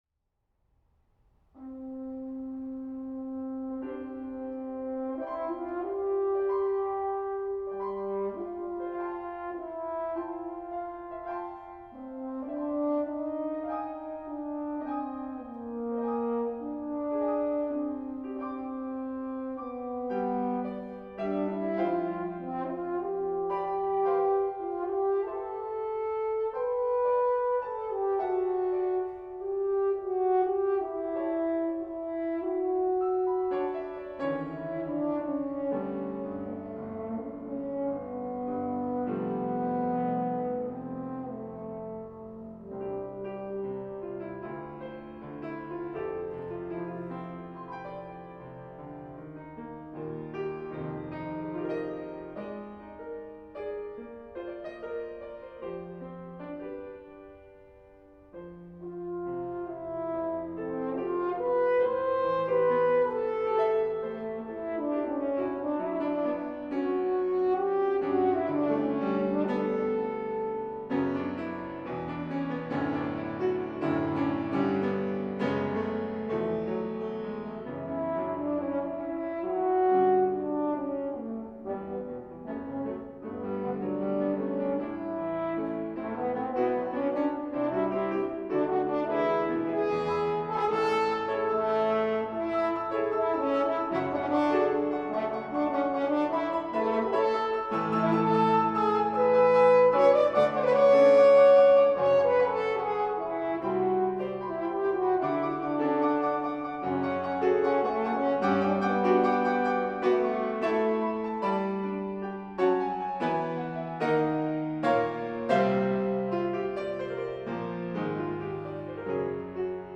horn
piano